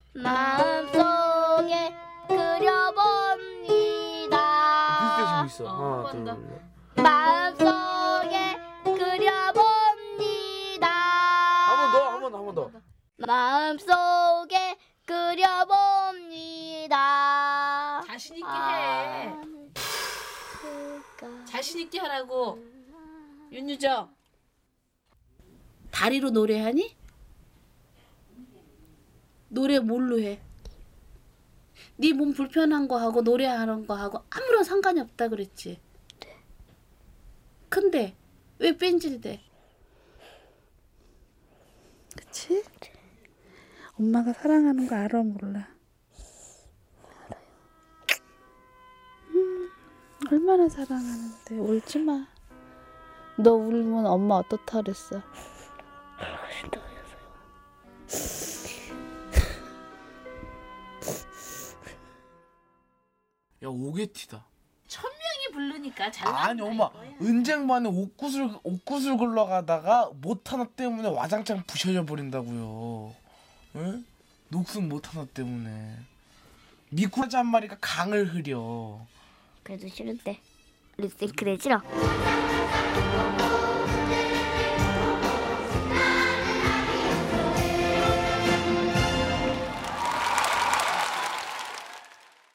즐거운 분위기와 장애인의 아픔을 대비시켰습니다.